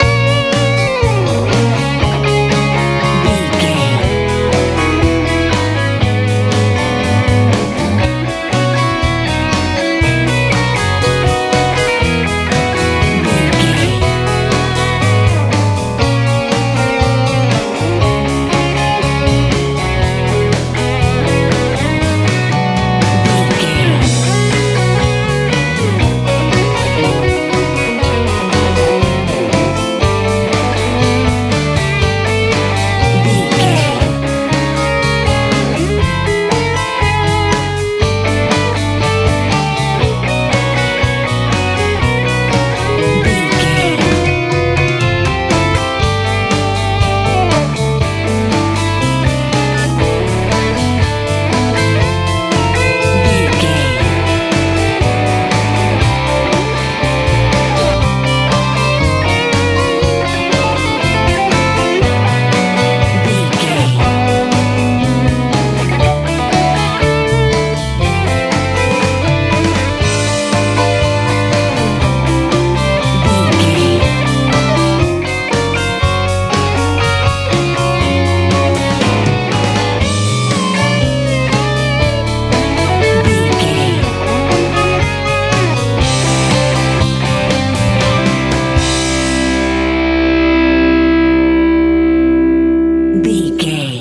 Ionian/Major
G♯
driving
energetic
dreamy
electric guitar
bass guitar
acoustic guitar
drums
lively